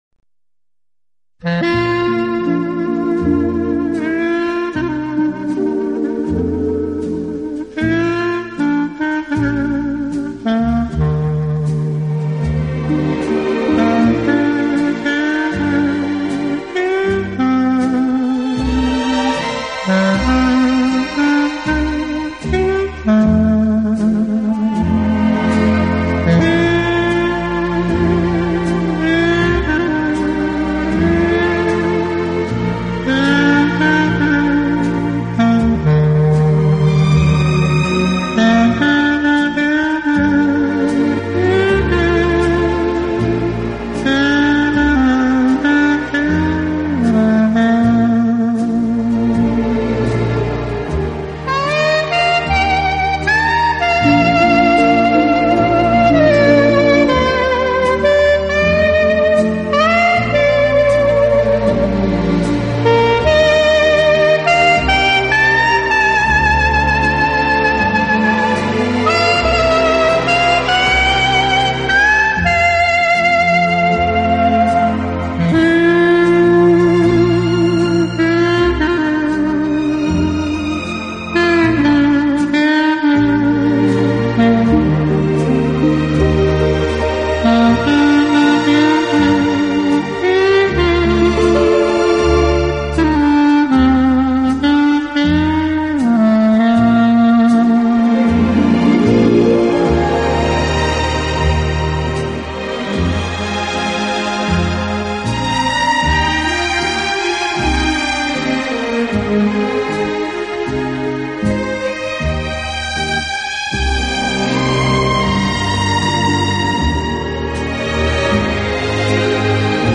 【浪漫单簧管】
Genre: Instrumental
Quality: MP3 / Joint Stereo